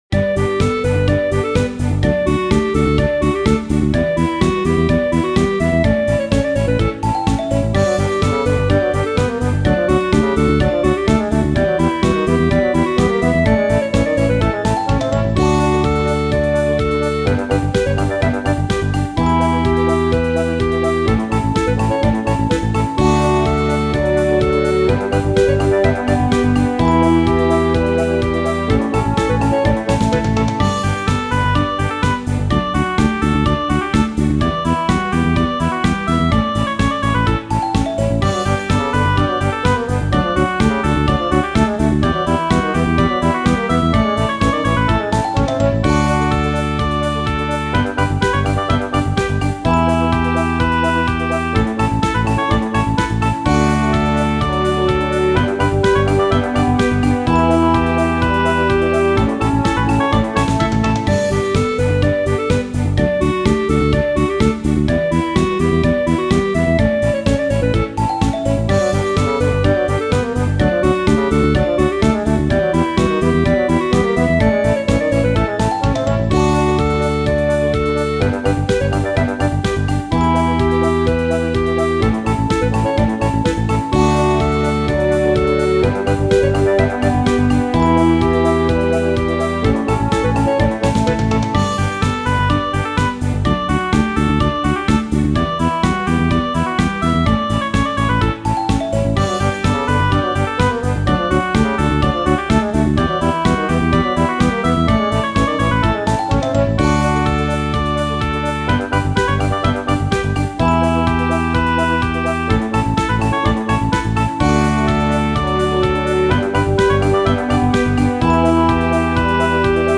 イメージ：明るい 軽やか   カテゴリ：RPG−街・村・日常